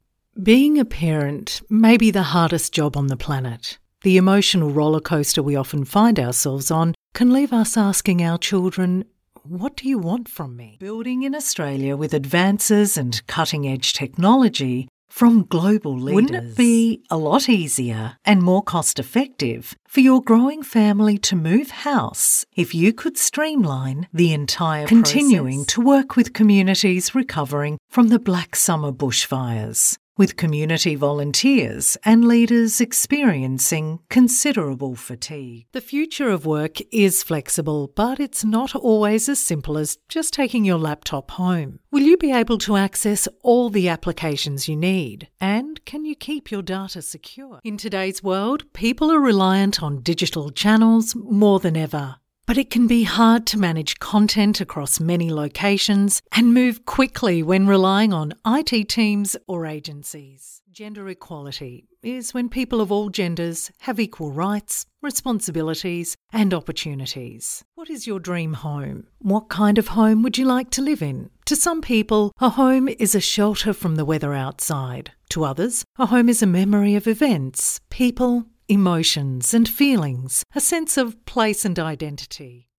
Female
Adult (30-50), Older Sound (50+)
Narration
Words that describe my voice are Natural, Warm, Australian Female Voice actor.
All our voice actors have professional broadcast quality recording studios.